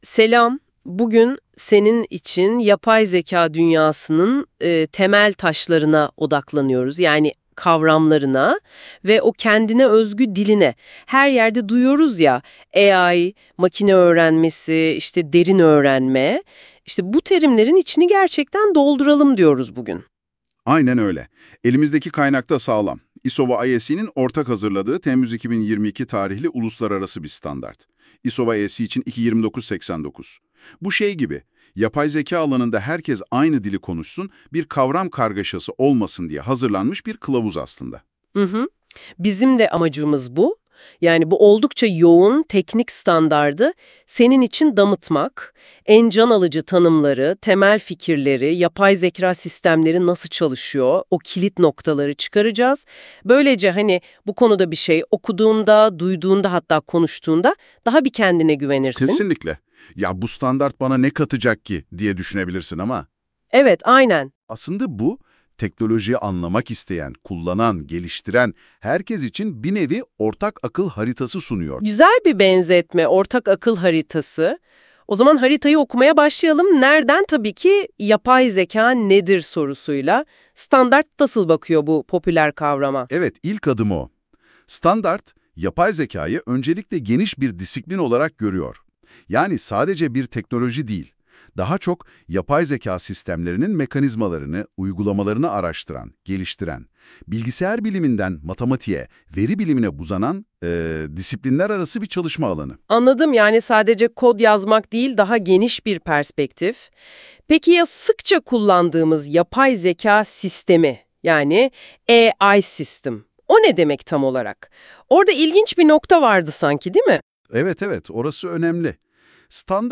ISO 22989 ve Yapay Zeka'nın temelleri ile nasıl işlediğinin ele alındığı 34 dakikalık kapsamlı bir söyleşi